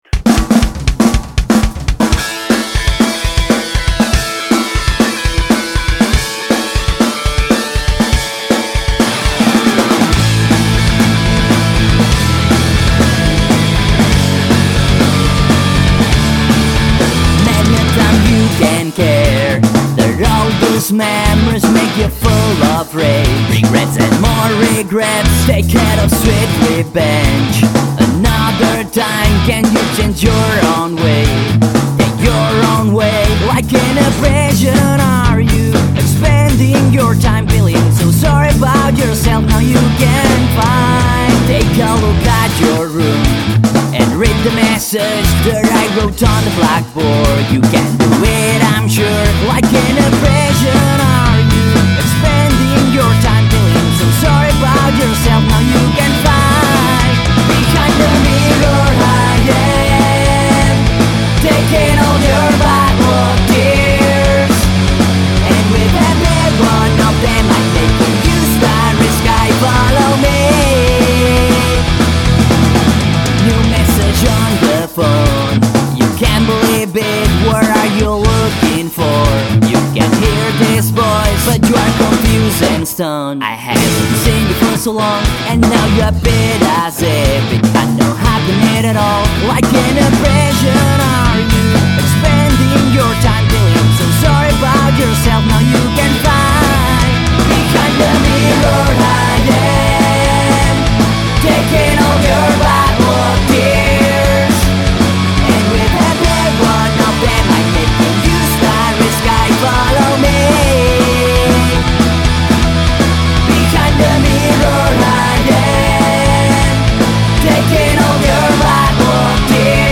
voz y guitarra
Bajo y voces
Bateria y voces.